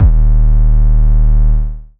TM88 MadDist808.wav